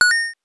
coin_6.wav